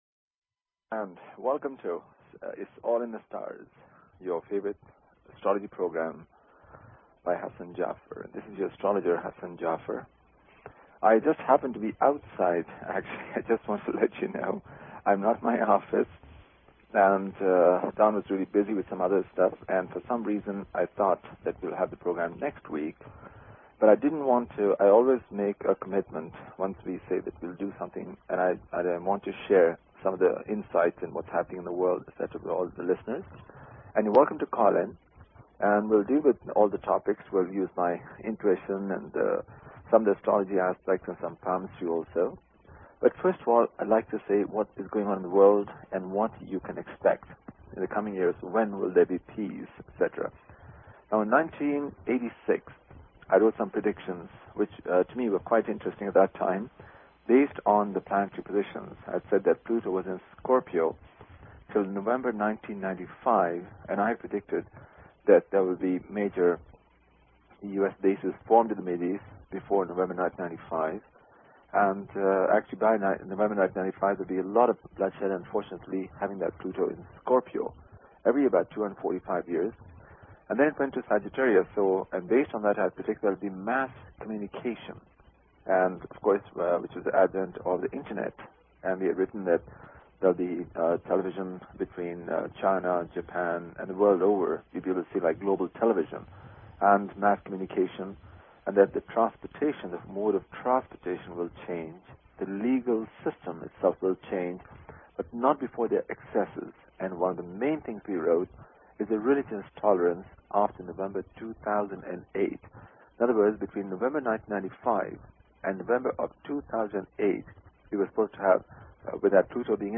Talk Show Episode, Audio Podcast, Its_all_in_the_Stars and Courtesy of BBS Radio on , show guests , about , categorized as